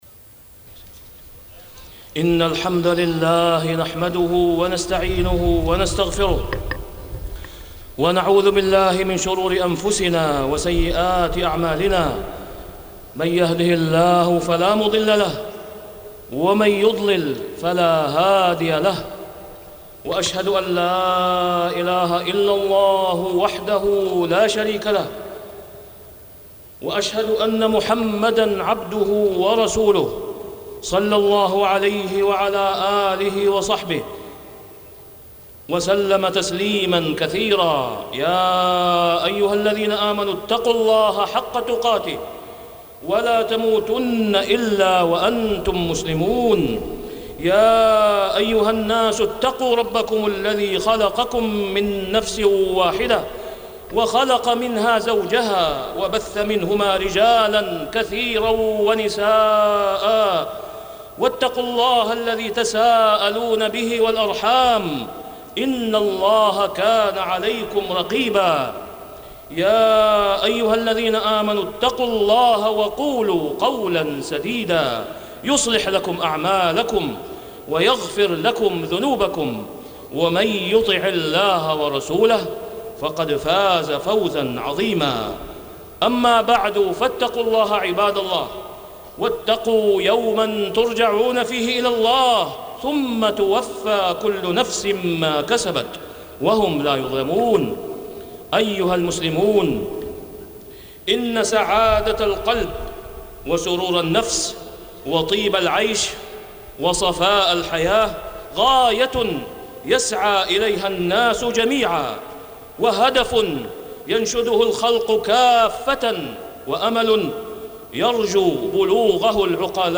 تاريخ النشر ٥ شوال ١٤٢٧ هـ المكان: المسجد الحرام الشيخ: فضيلة الشيخ د. أسامة بن عبدالله خياط فضيلة الشيخ د. أسامة بن عبدالله خياط سلامة الصدر